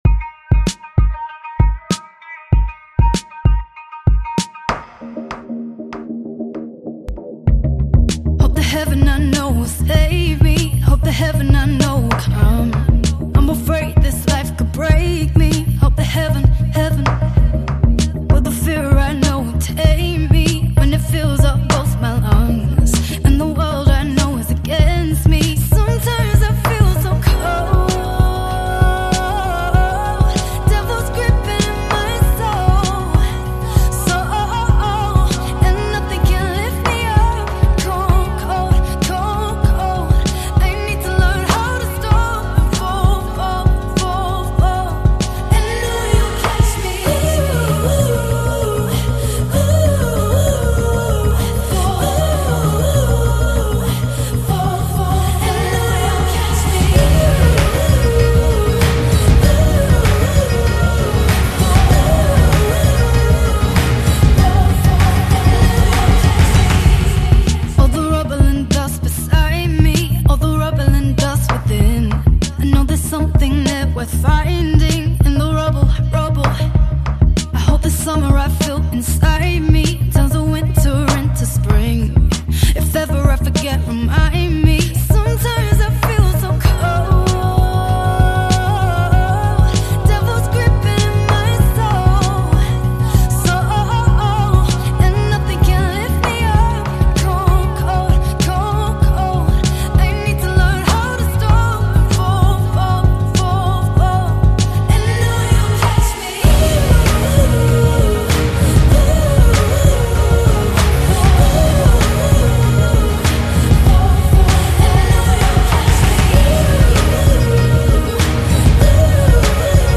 The surging song